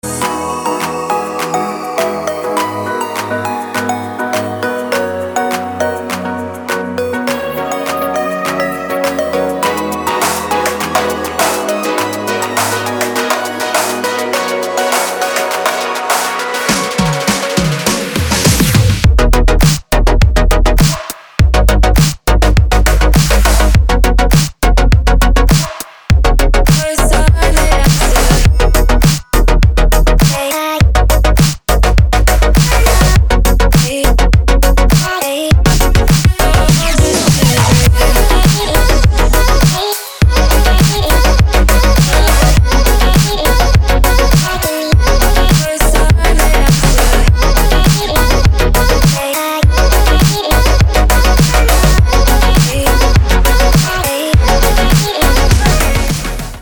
красивые
dance
Electronic
электронная музыка
спокойные
club